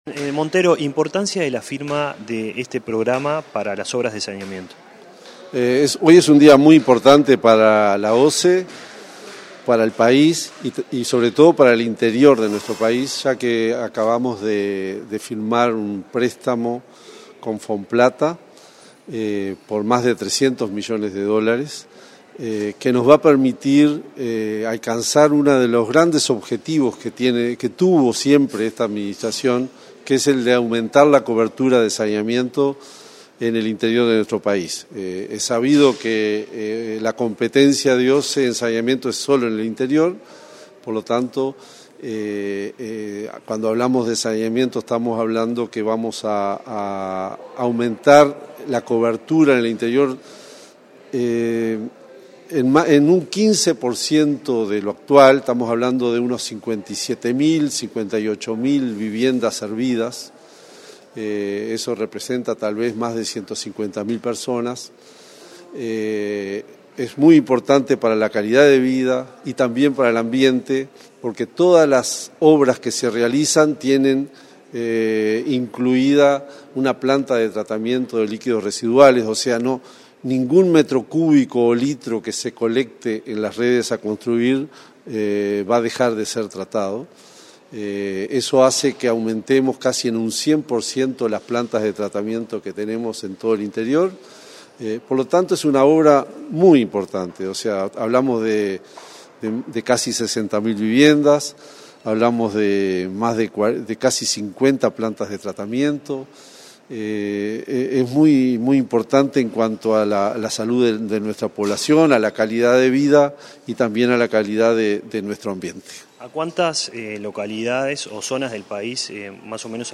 Entrevista al presidente de OSE, Raúl Montero
Tras el evento, el presidente de OSE, Raúl Montero, realizó declaraciones a Comunicación Presidencial.